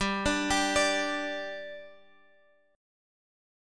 G5 chord {x 10 12 12 x 10} chord